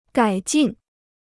改进 (gǎi jìn) Free Chinese Dictionary